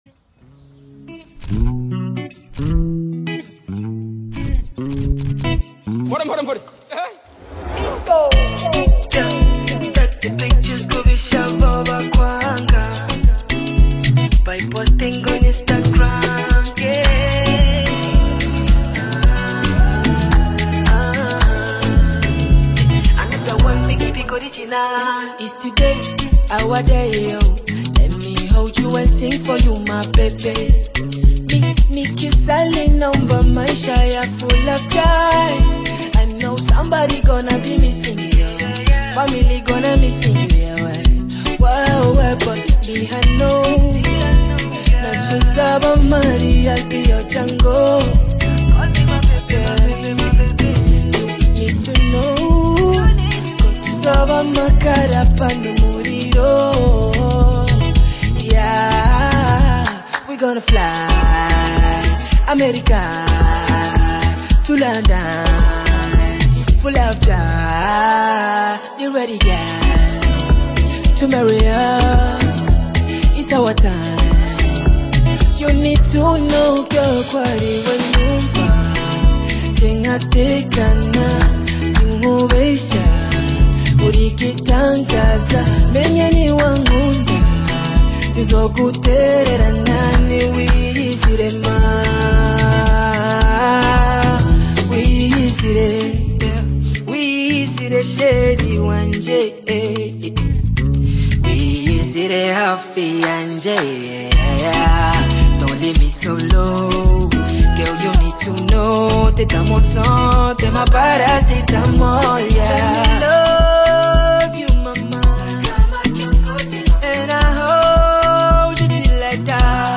Afro-Pop/urban single